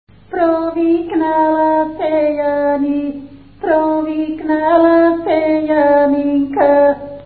музикална класификация Песен
размер Две четвърти
фактура Едногласна
начин на изпълнение Солово изпълнение на песен
битова функция На хоро
фолклорна област Средна Западна България
начин на записване Магнетофонна лента